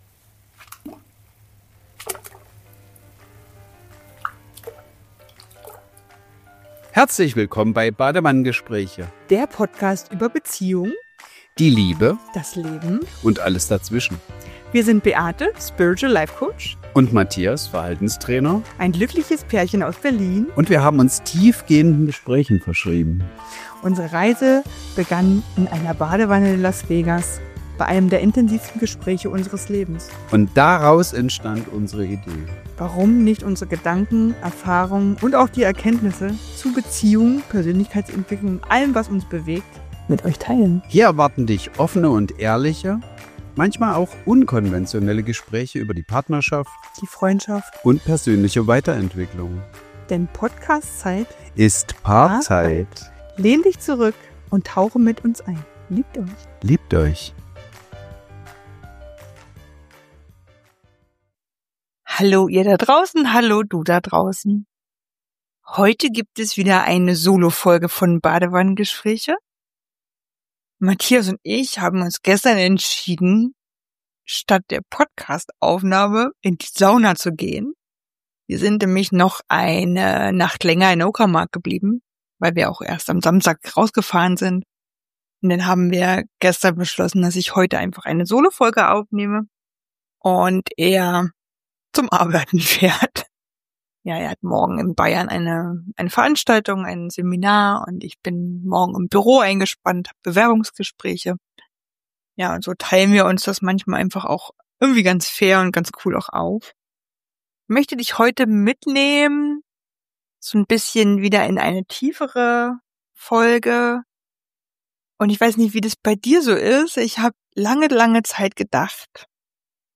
Der Raum der Stille: Eine Handpan-Klangreise – Badewannengespräche – Podcast